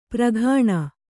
♪ praghāṇa